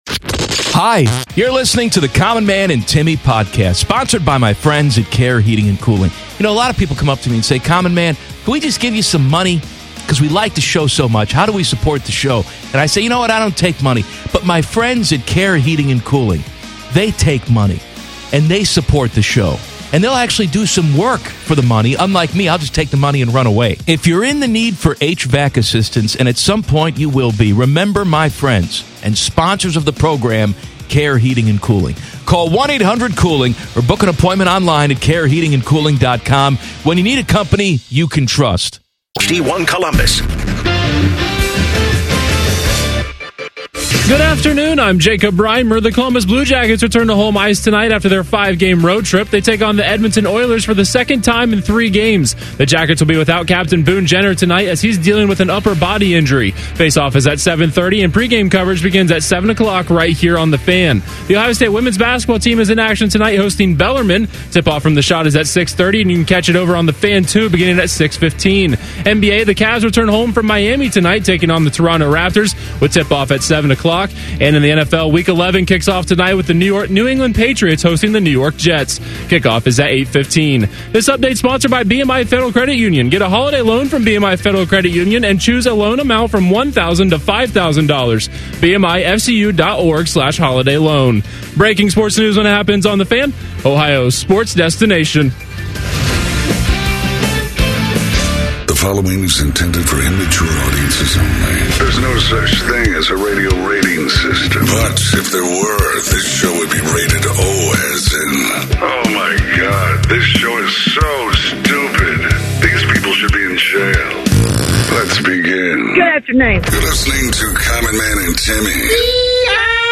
Sports